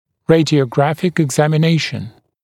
[ˌreɪdɪəu’græfɪk ɪgˌzæmɪ’neɪʃn] [eg-][ˌрэйдиоу’грэфик игˌзэми’нэйшн] [эг-]рентгенологическое обследование, рентгенологическое исследование